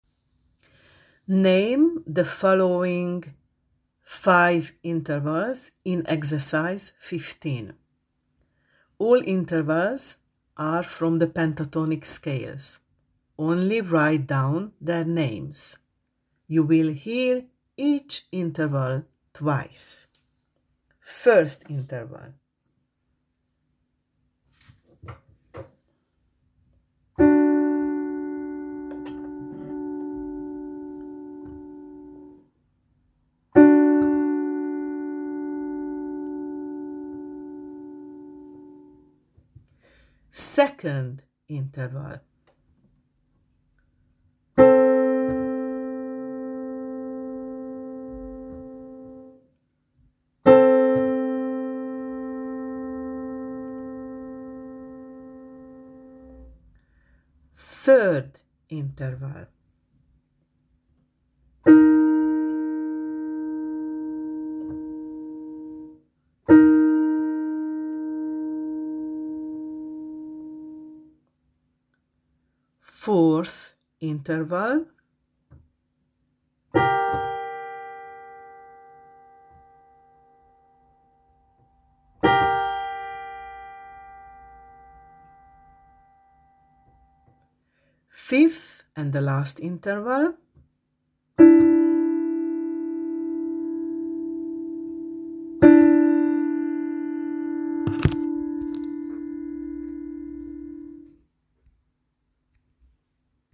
Only write down their names: Example: M3, P5 etc. You will hear each interval twice: